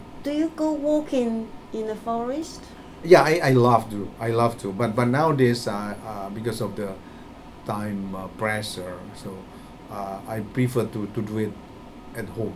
S1 = Taiwanese female S2 = Indonesian male Context: They are talking about doing exercise and keeping fit.
The problem seems to arise because the medial consonant in pressure is pronounced as [s] rather than the expected [ʃ] .